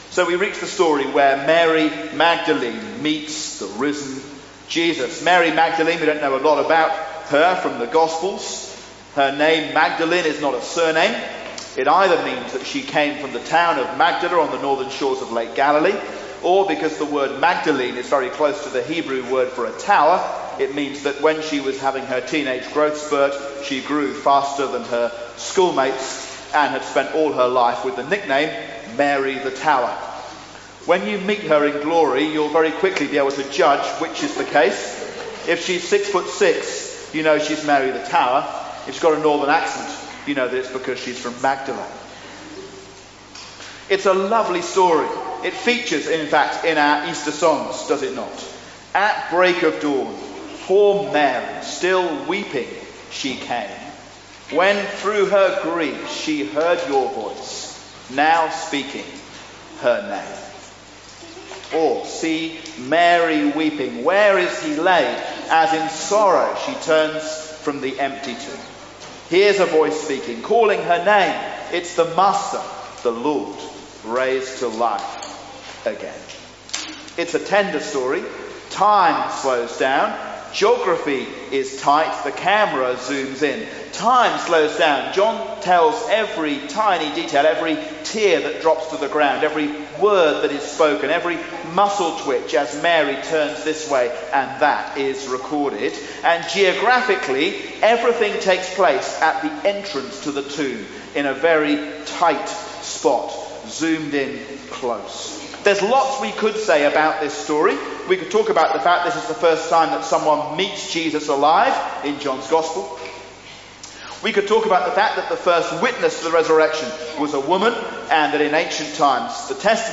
A sermon on John 20:11-18